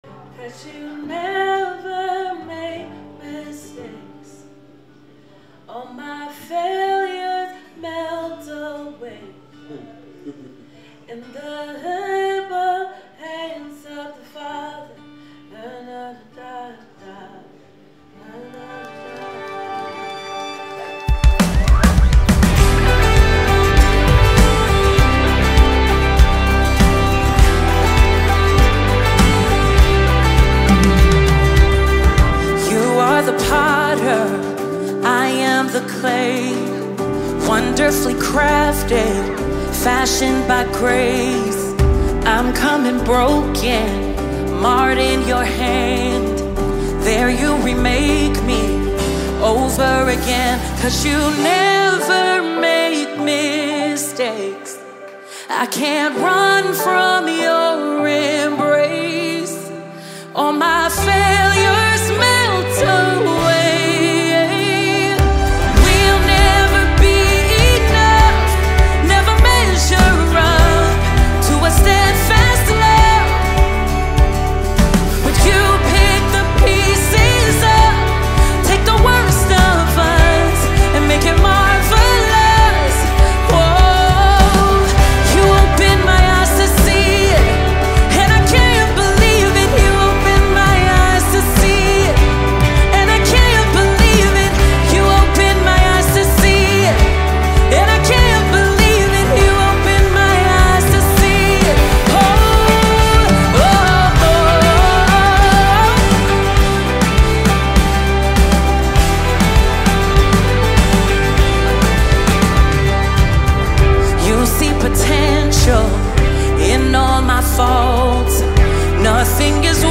powerful and uplifting anthem